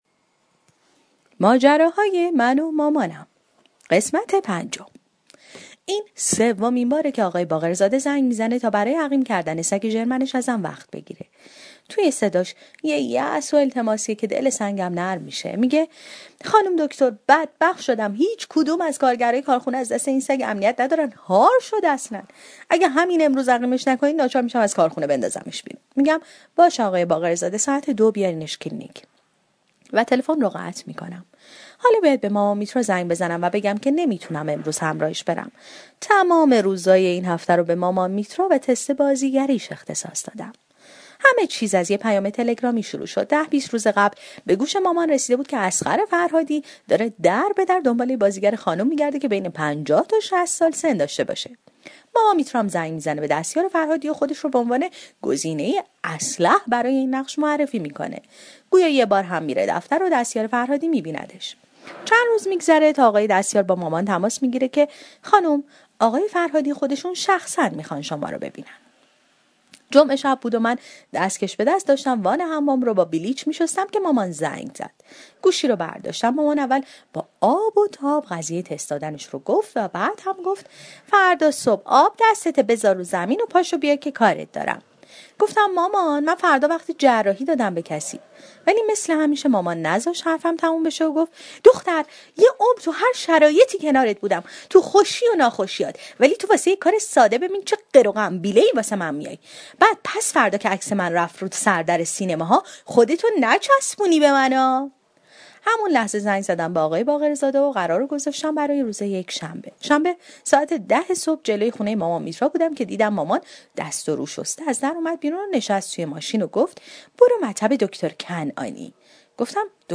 طنز صوتی / ماجراهای من و مامانم 5